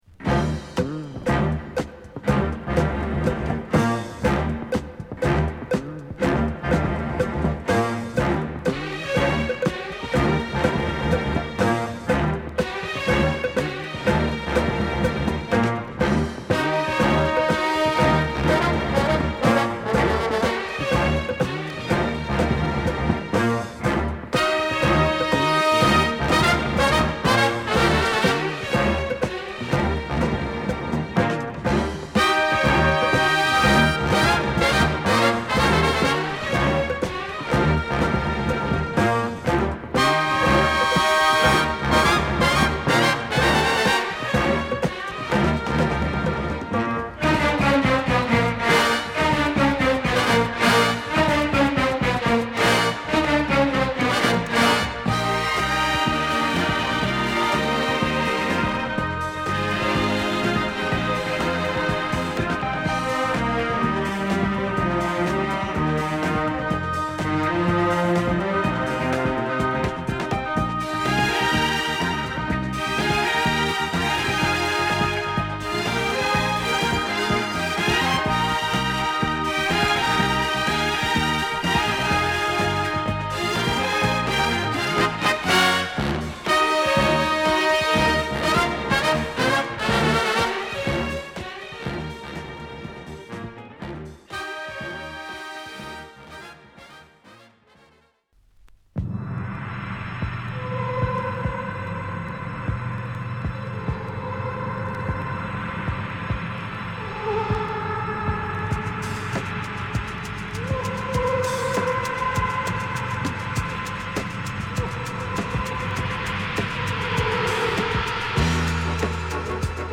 ＊SideB大きなラインありますがPlayはOKです。